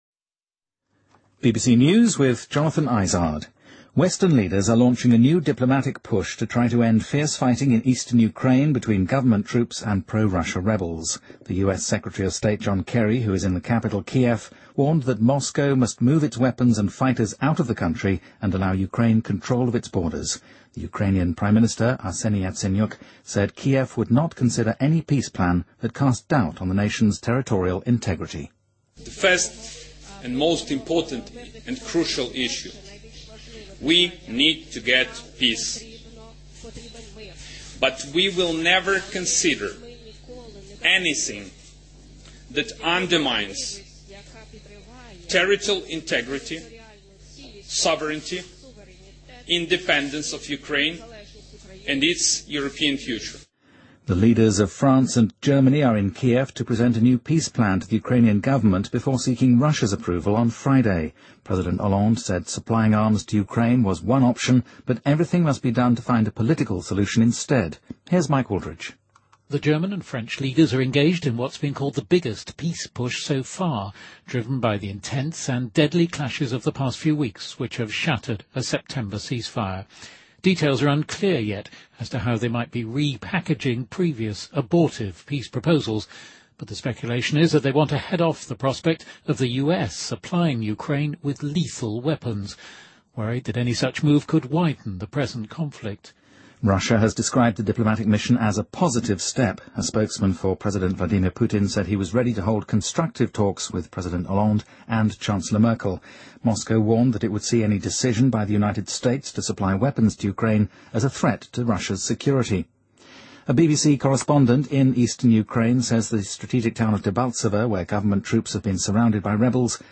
BBC news